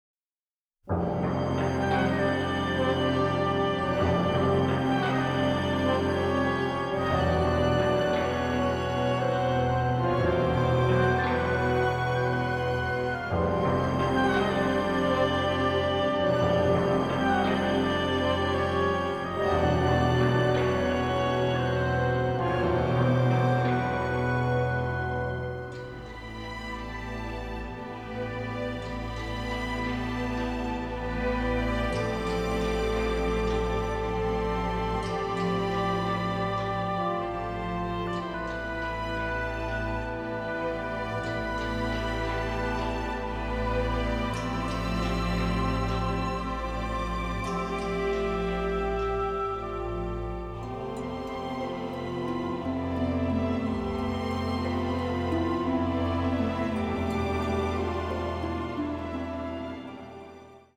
symphonic score
a sensitive, powerful, highly melodic score
from the original album stereo master tapes